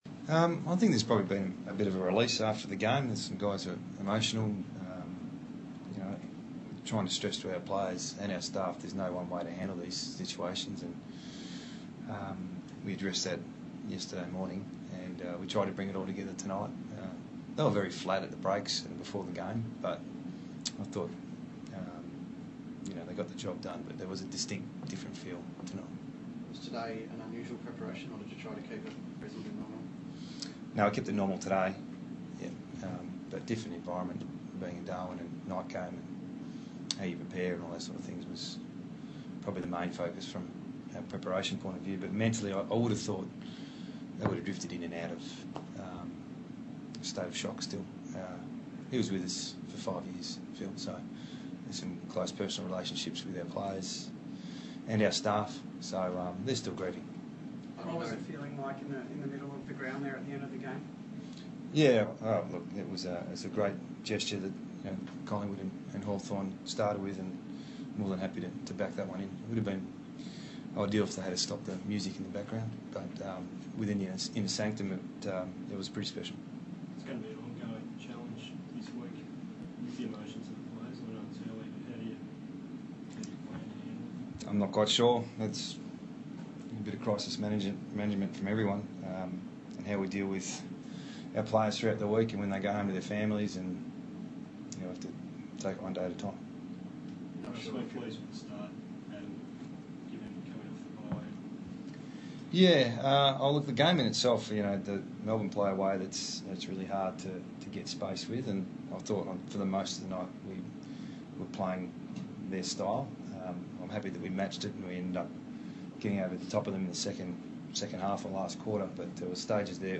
Round 14 Adam Simpson Post Game
West Coast Eagles Coach